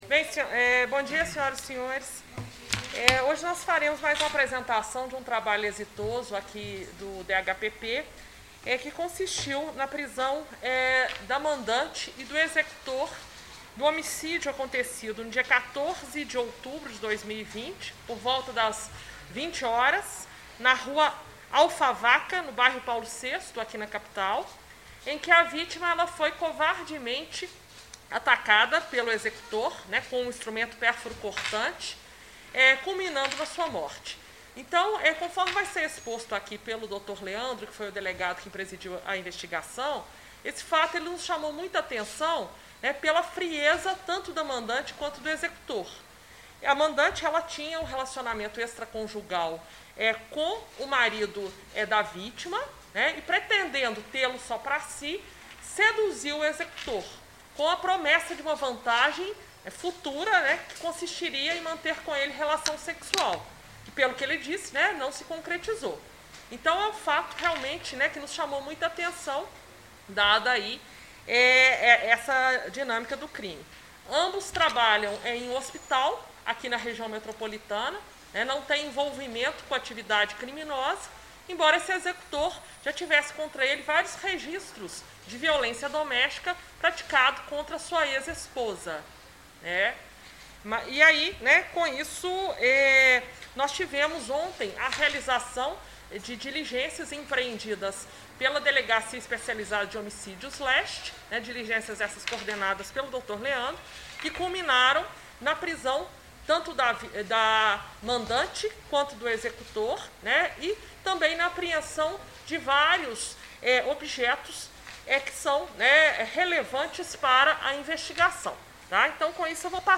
Coletiva-Homicidio.mp3